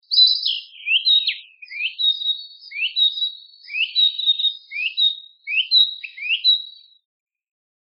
キビタキ（黄鶲）の鳴き声。
「ピッコロロ、ピッコロロ」と美しい声で鳴きます。「チュリリリ、チュリリリ」または「チュルル、チュルル」と、短いフレーズを繰り返すようなさえずりが特徴的です。。